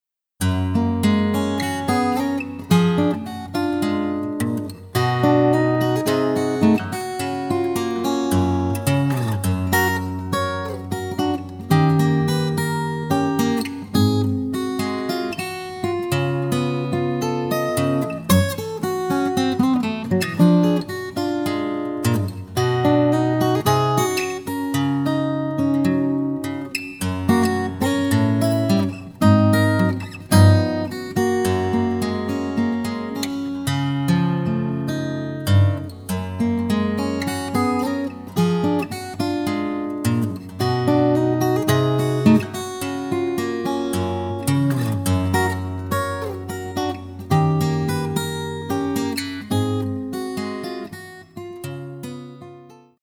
●情緒的でメロディアスなオリジナル